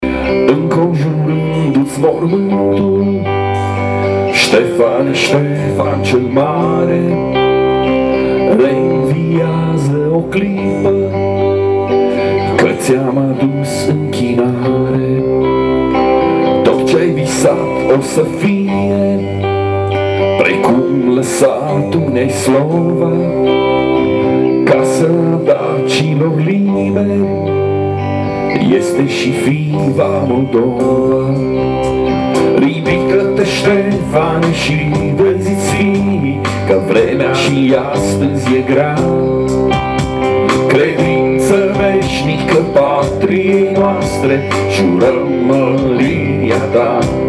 chitară bas
clape
voce și chitară.